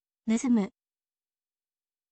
nusumu